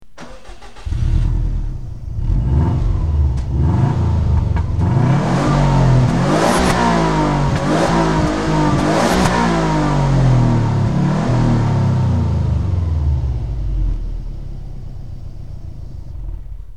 Sons de moteurs subaru - Engine sounds subaru - bruit V8 V10 subaru
IMPREZA WRX STI - point mort.mp3